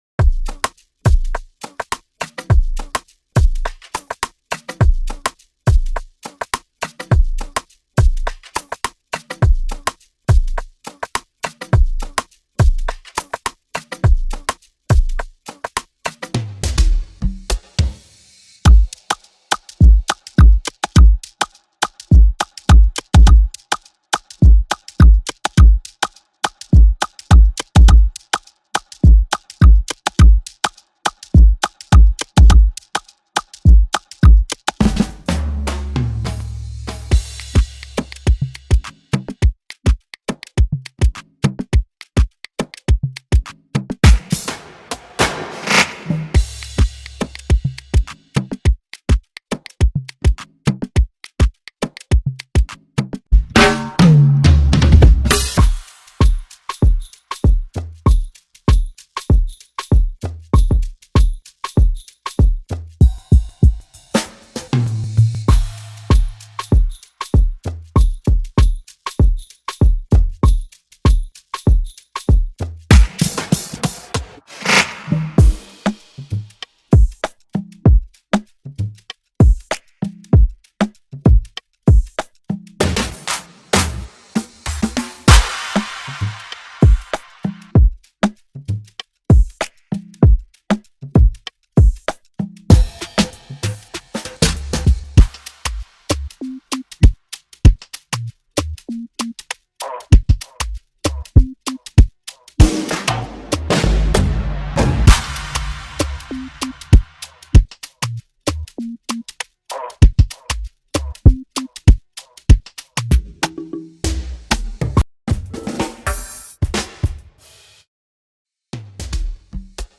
Afrobeat drum rolls and drum fills are perfect transitioning elements to use in your beats
– 30+ High-Quality AfroBeat Drum Roll/Drum Fills